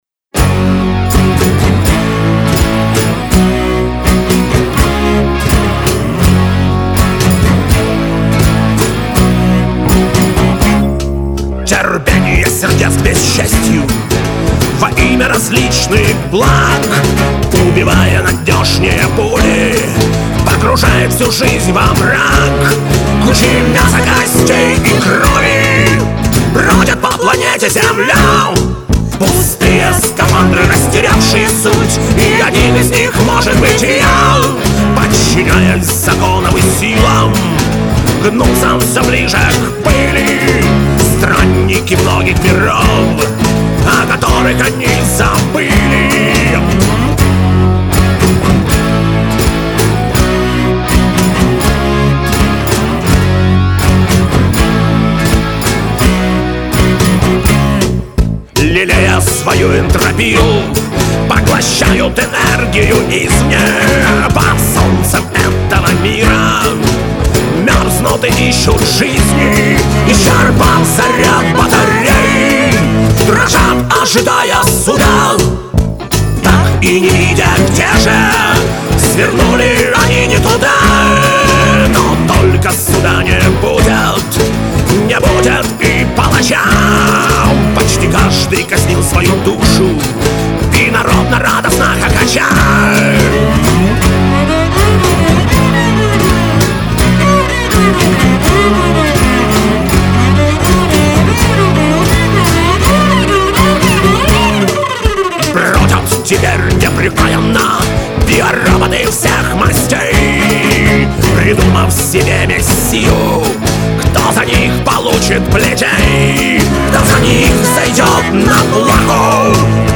Жанр: дарк фолк-панк
вокал, гитары
перкуссия, скиффл-инструменты.
ритм-гитара.
бас.
джа-кахон.
виолончель.